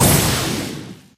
gene_atk_hit_02.ogg